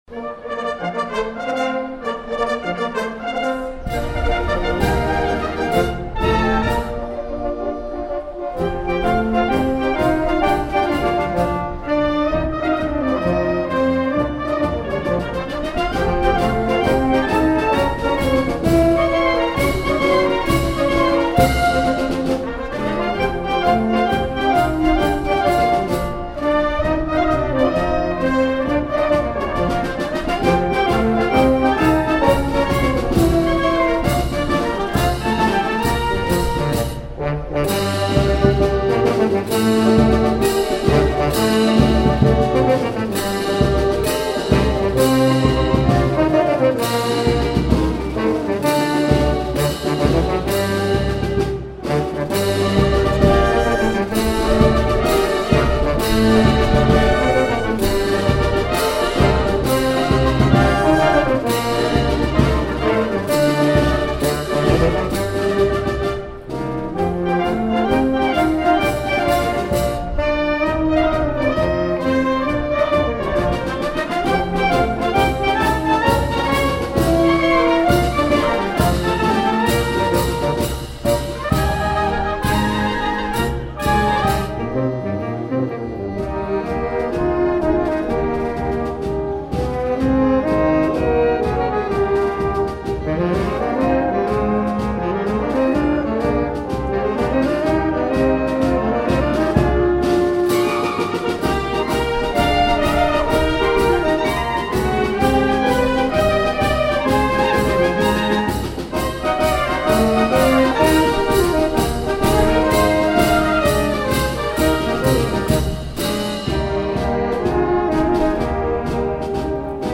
Marcia Militare